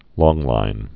(lônglīn, lŏng-)